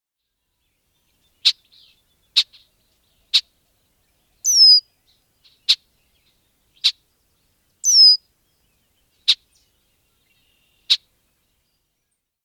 Red-winged Blackbird
How they sound: The typical call of a Red-winged Blackbird is a distinctive, matter-of-fact check sound.